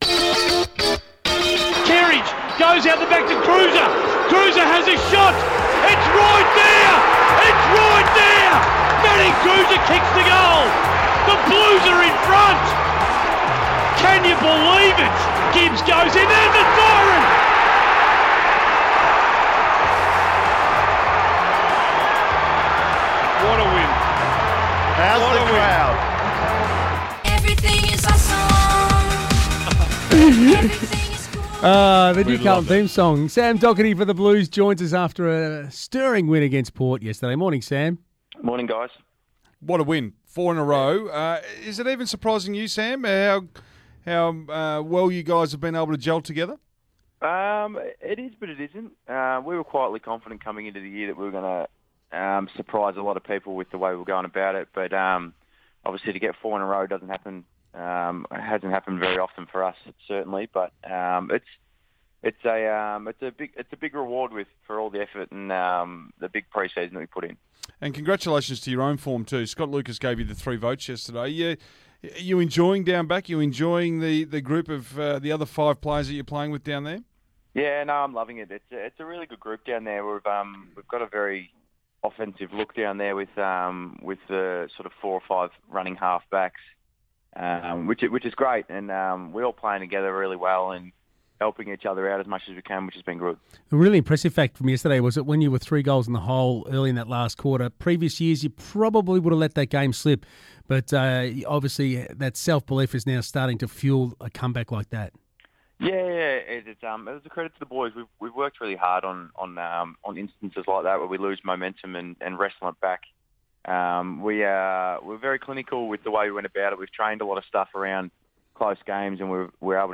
Carlton defender Sam Docherty speaks to SEN Breakfast the morning after the Blues' two-point victory over Port Adelaide.